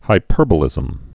(hī-pûrbə-lĭzəm)